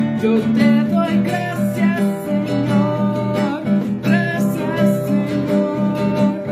gracias mi senor Meme Sound Effect